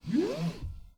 Звуки ремня безопасности
Без щелчка